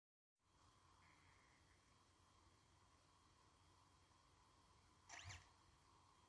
Wortmann Terra Mobile 2300 Bildschirm bleibt schwarz und komisches Geräusch alle paar Sekunden
Jetzt bleibt der Bildschirm beim Starten schwarz und es ertönt nur ein Geräusch alle paar Sekunden. Die Power-LED leuchtet noch und der Lüfter läuft auch ganz normal. Ich habe schon die Festplatte und das DVD-Laufwerk entfernt, das Geräusch bliebt aber immer noch.